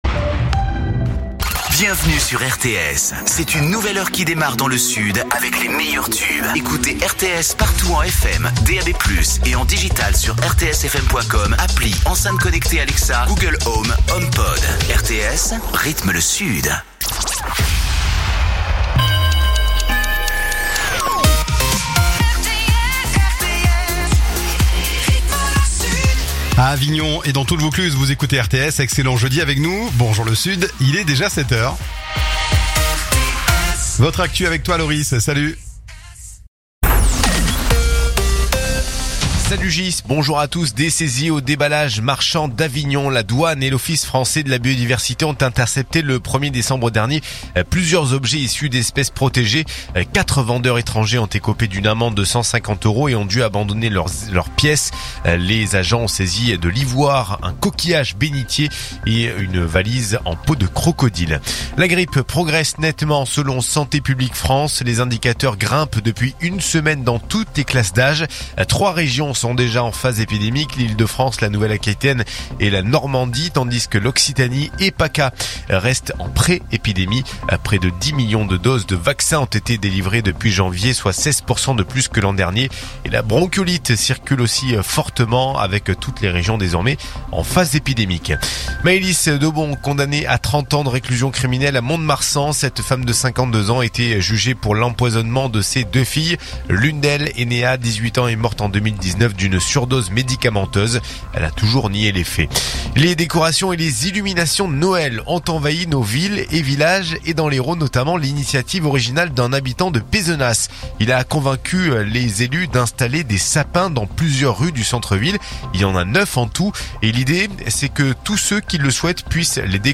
RTS : Réécoutez les flash infos et les différentes chroniques de votre radio⬦